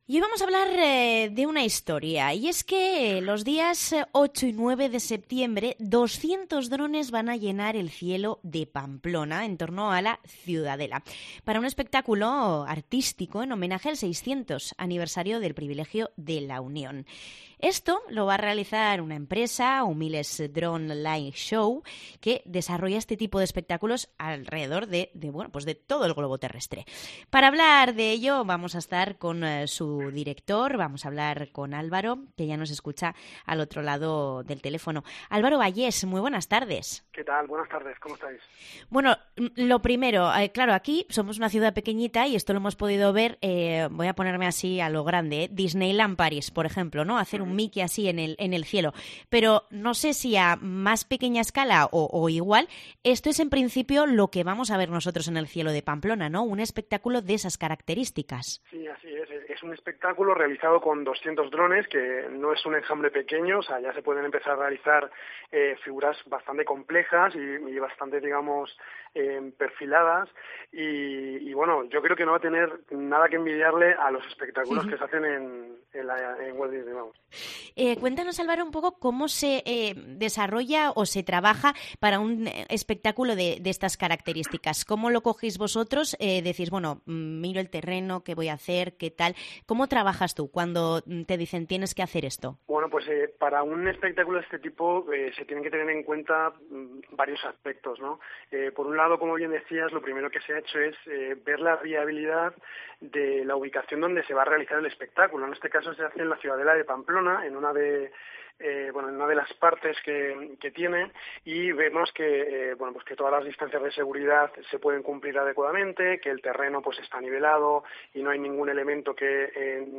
Cope Navarra